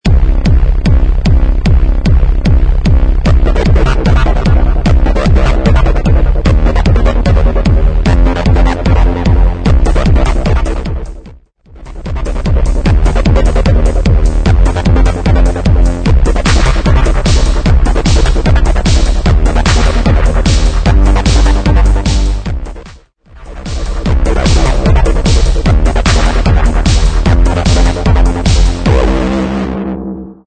150 BPM
Hard Electronic